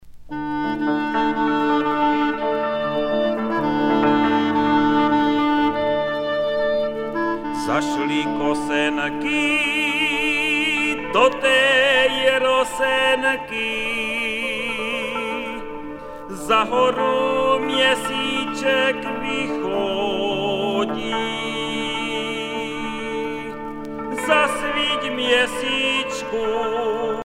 Folk singer from Moravian Wallachia
Pièce musicale éditée